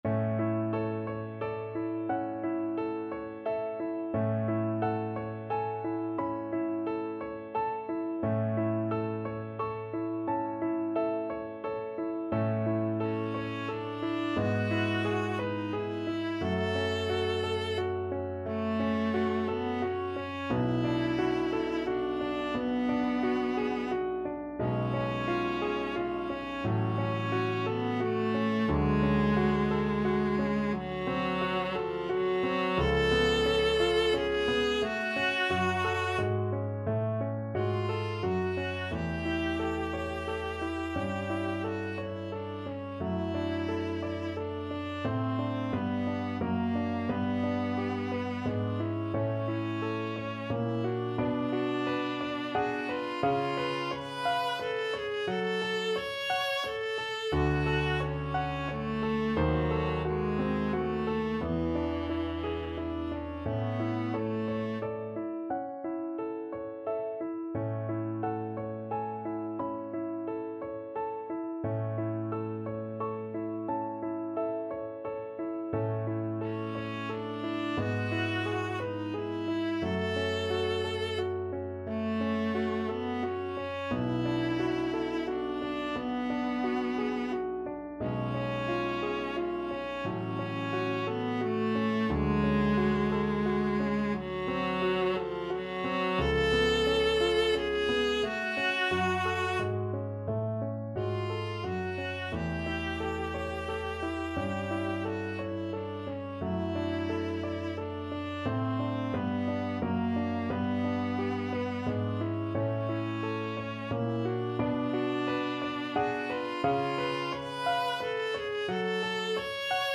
6/4 (View more 6/4 Music)
~ = 88 Andante
Classical (View more Classical Viola Music)